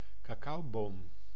Ääntäminen
Ääntäminen Tuntematon aksentti: IPA: /kɑˈkɑu̯bom/ Haettu sana löytyi näillä lähdekielillä: hollanti Käännös Substantiivit 1. cacaoyer {m} Suku: m .